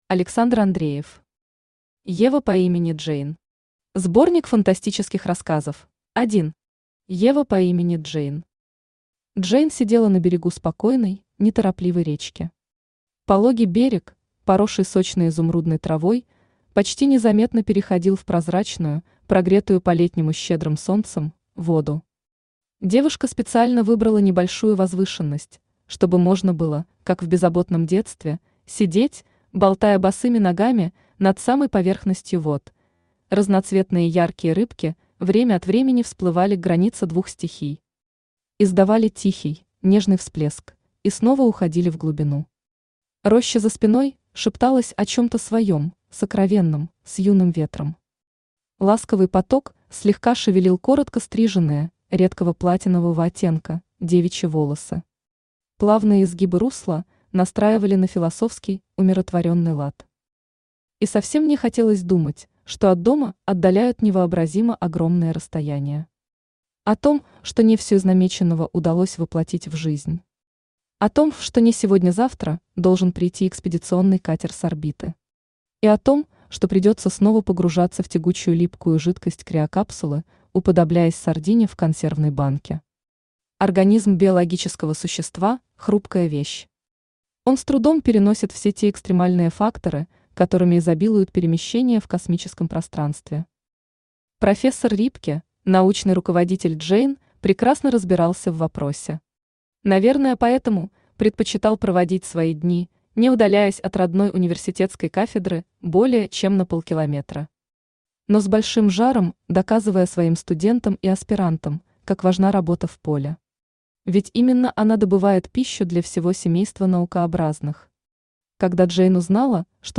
Аудиокнига Ева по имени Джейн.
Сборник фантастических рассказов Автор Александр Владимирович Андреев Читает аудиокнигу Авточтец ЛитРес.